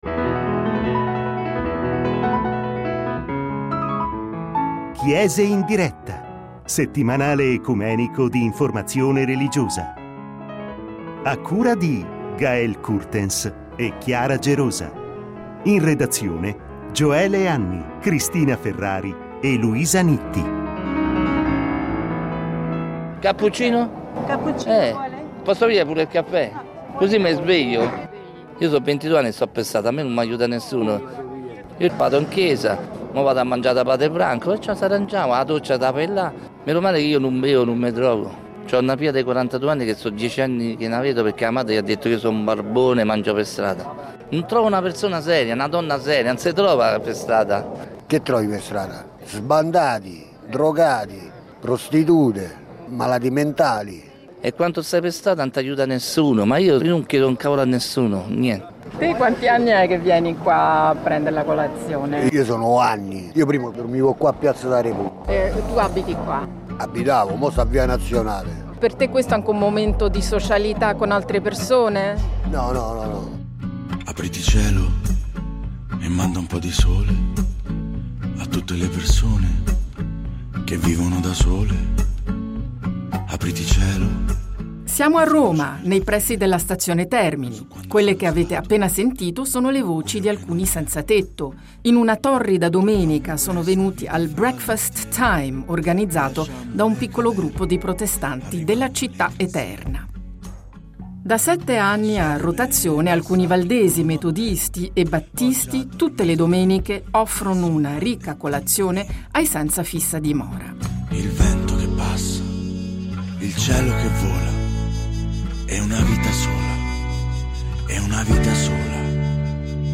Lo scopriamo in questo reportage realizzato in una torrida domenica romana.